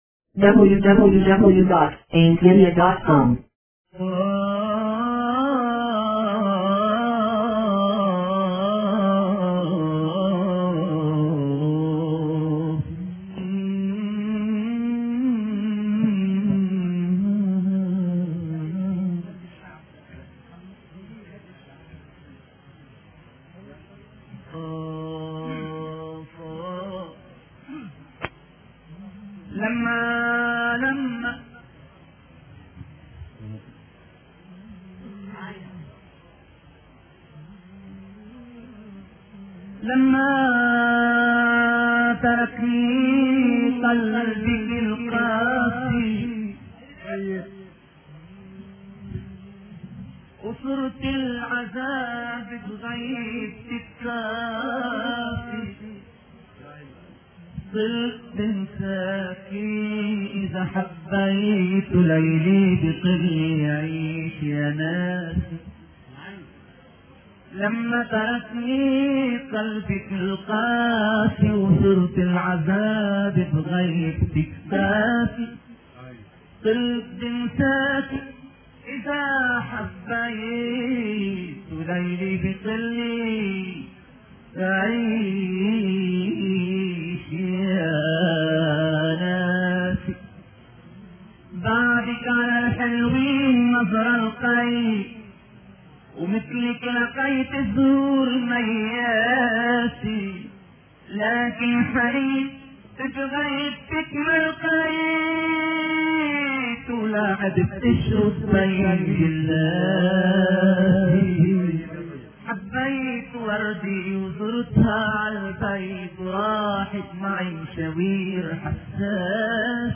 زجل لبناني